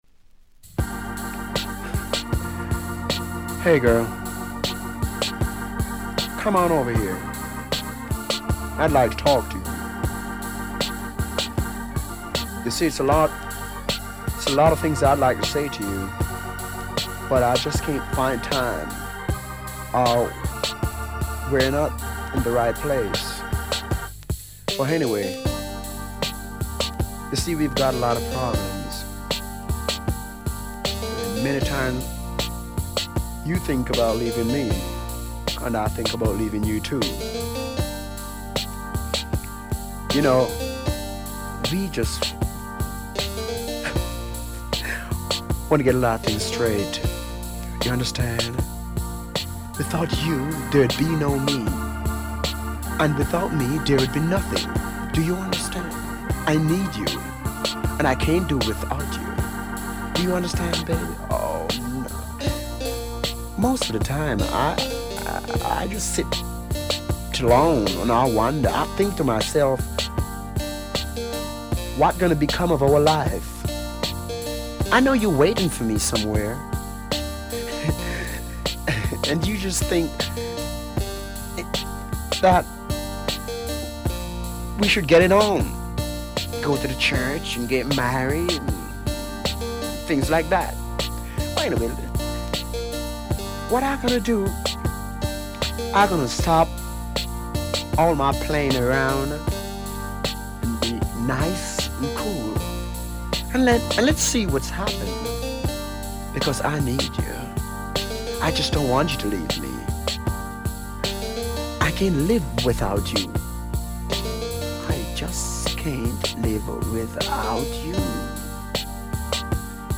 soul music. Amazing minimalist bluesy soul
including synthesizer, organ and drumbox